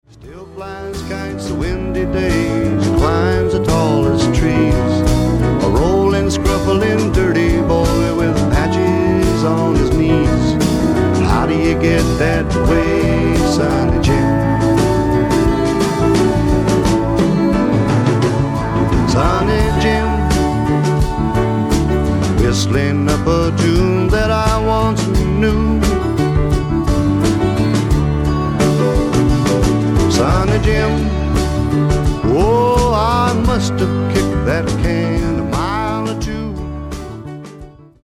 SSW / SWAMP ROCK